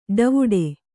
♪ ḍavuḍe